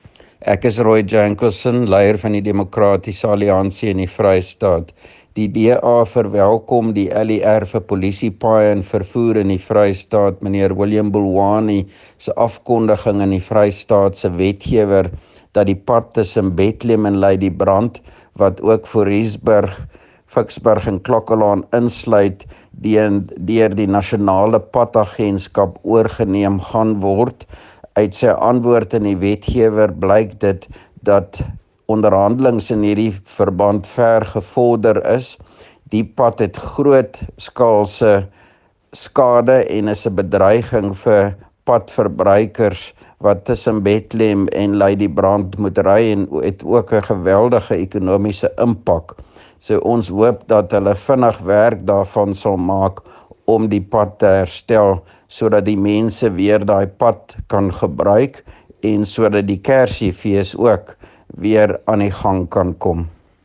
Afrikaans by Dr Roy Jankielsohn MPL
Afrikaans-soundbite.mp3